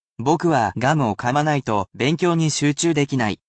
Word of the Week is special in that you do not simply learn a Japanese word or phrase that I will pronounce for you personally, but you can absorb so many other morsels of knowledge.
[casual speech]